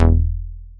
DOWN BASS E3.wav